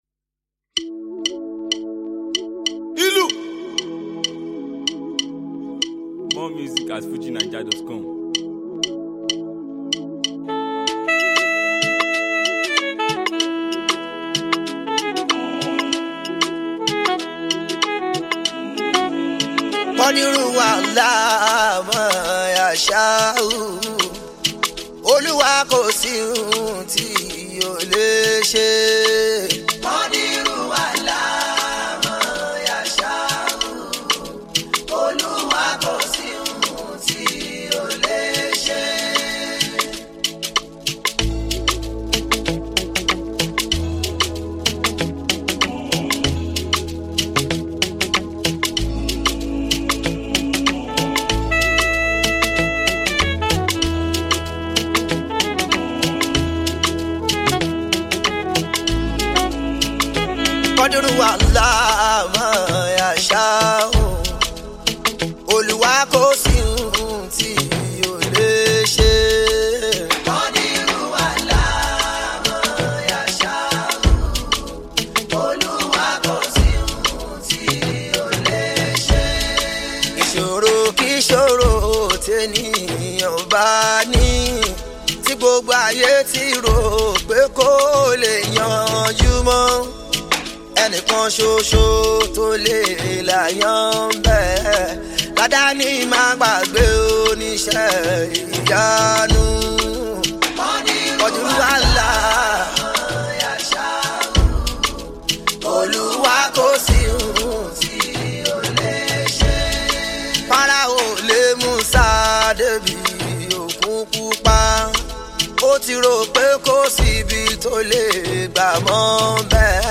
Yoruba Islamic Music